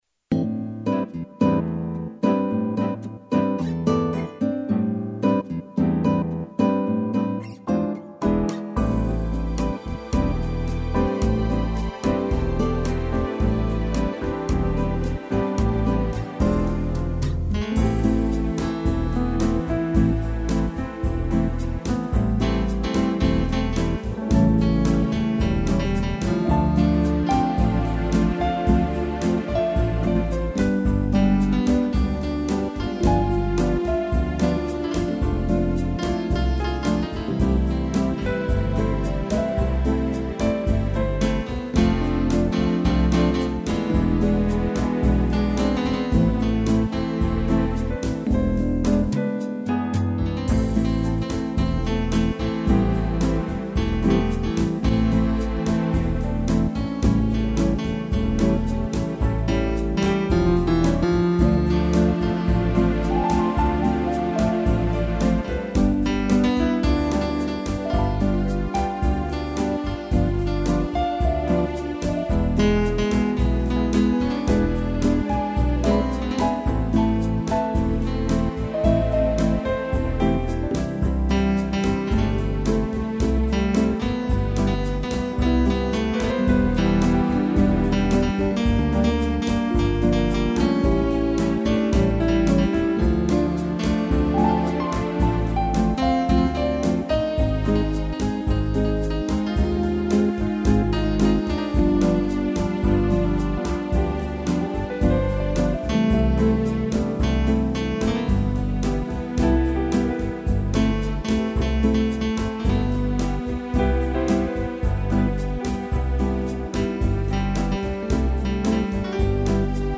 instrumental
piano